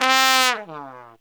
Index of /90_sSampleCDs/Best Service ProSamples vol.25 - Pop & Funk Brass [AKAI] 1CD/Partition C/TRUMPET FX3